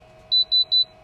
As of firmware version 8.0.1, all units now play various sound sequences with ZTD deployments.
If the process is successful, the box will play the following sound: